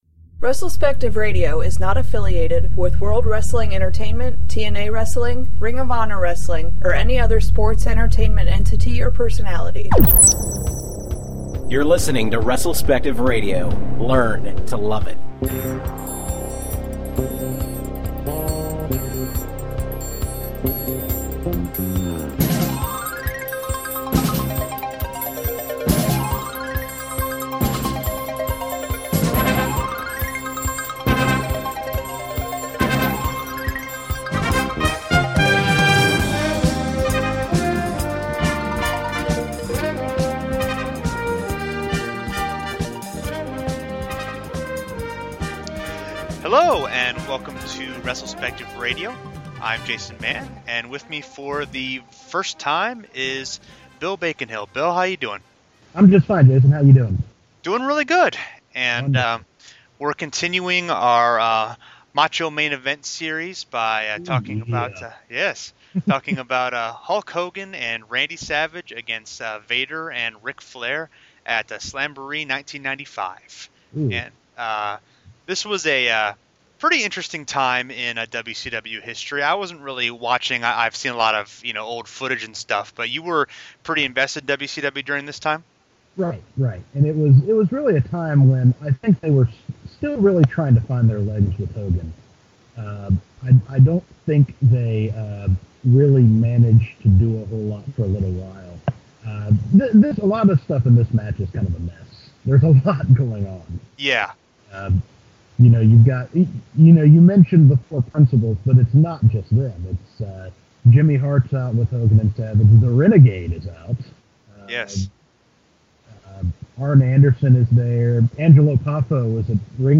They also discuss a lot of the highs and lows of WCW in the mid-1990s. Note: Unfortunately, there are some unavoidable sound-quality problems with this episode, but we hope we've made it listenable.